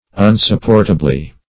Meaning of unsupportably. unsupportably synonyms, pronunciation, spelling and more from Free Dictionary.
-- Un`sup*port"a*bly , adv.